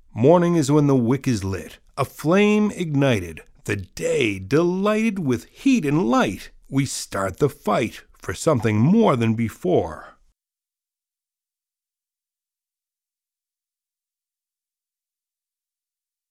So, I created some more and had them recorded by two talented voice-over actors and offer them here free to share. Each one includes a bit of silence at the end so that it plays once, pauses for a few moments, and then plays again.